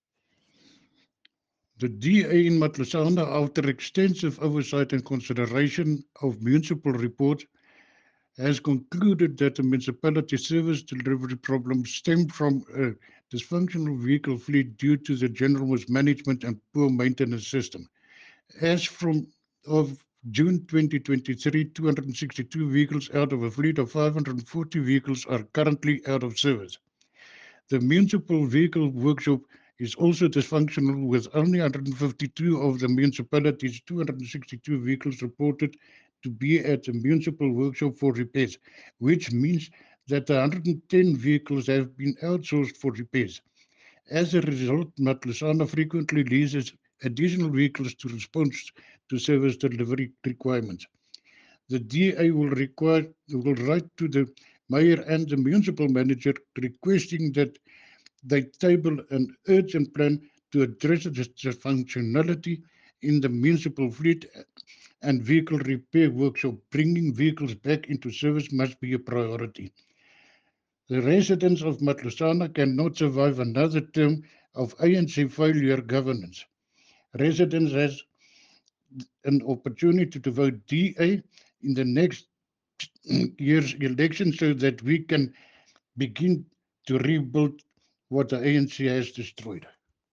Afrikaans by Cllr Johan Bornman.
Rdl.-Johan-Bornman-Vloot-Afr.mp3